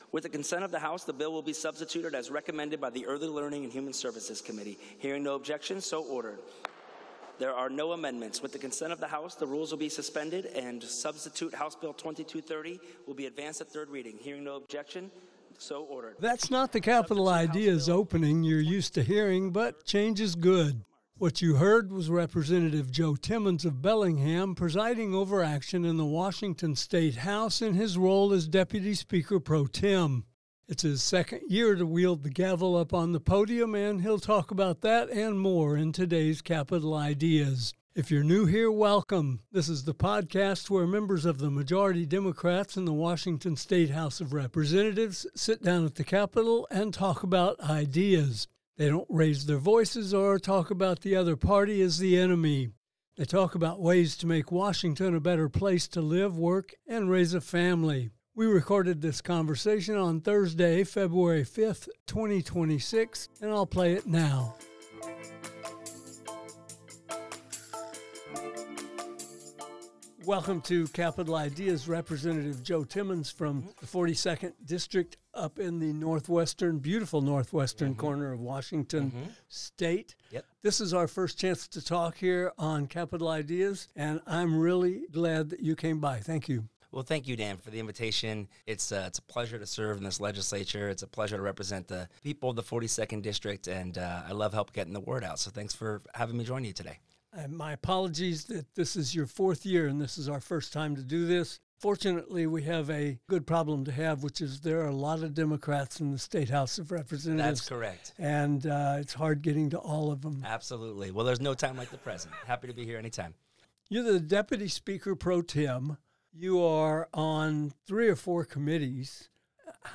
Today we’re visiting with Rep. Joe Timmons from Bellingham, up in the 42nd district.